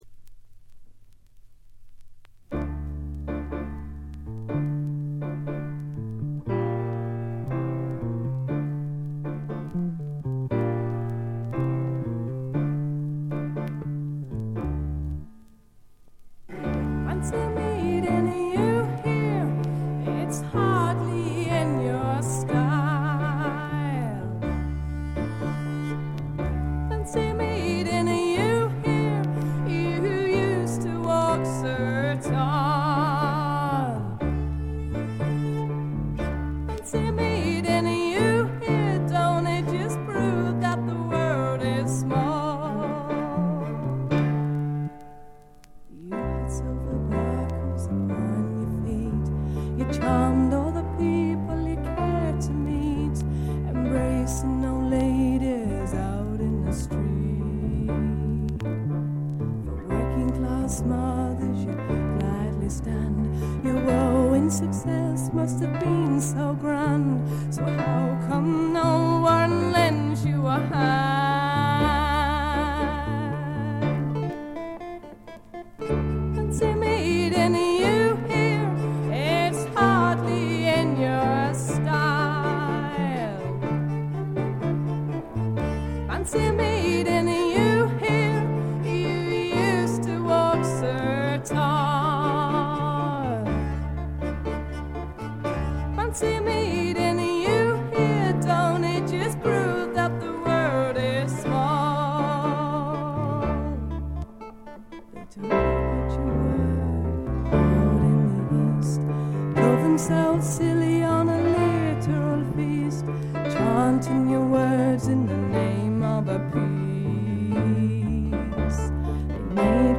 軽微なバックグラウンドノイズ。
試聴曲は現品からの取り込み音源です。